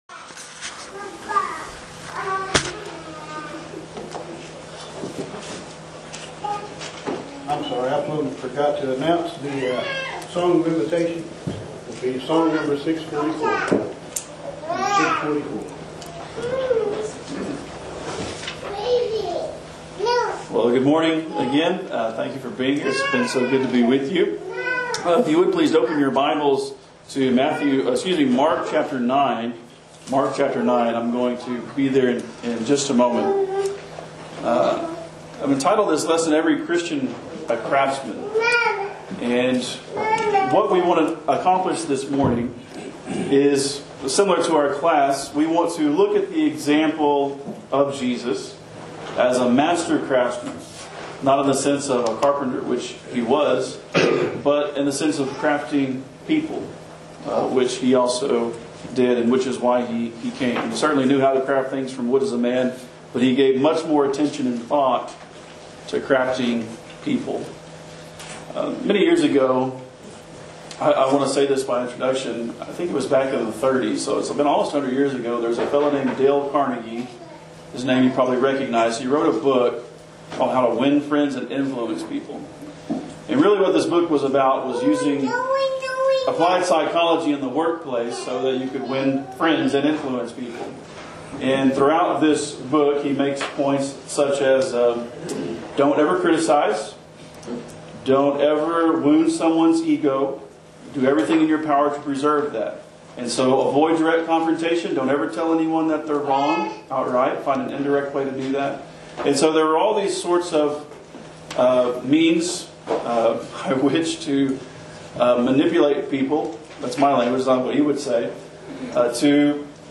Every Christian a Craftsman- Gospel meeting